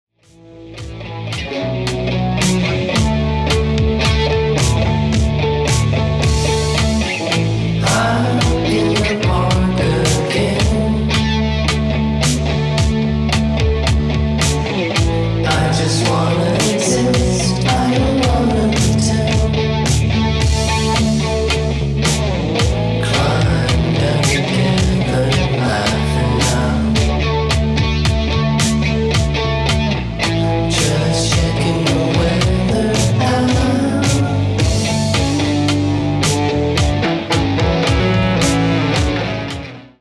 全編に渡って脱力感の漂うベッドルーム・ポップ/シンセ・ファンク/ダウンテンポetcを展開しています。